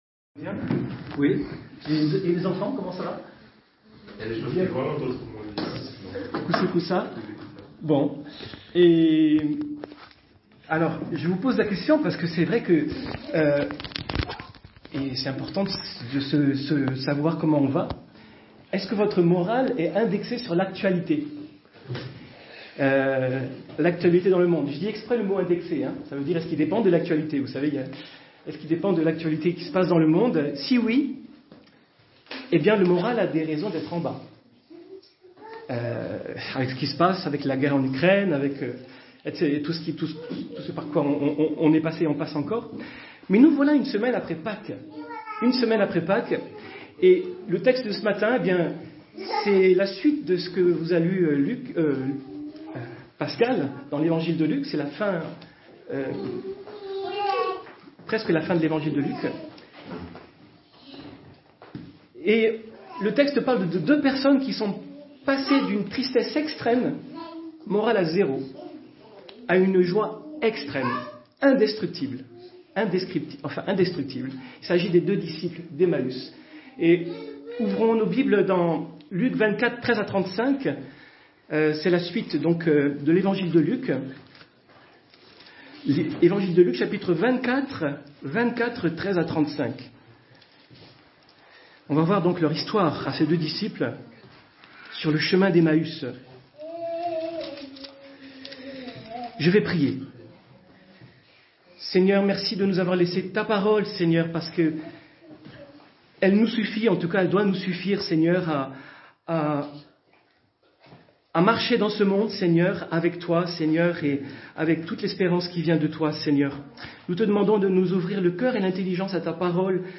Culte du dimanche 24 avril 2022 - EPEF
Prédication De la tristesse à la joie Luc 24.13-35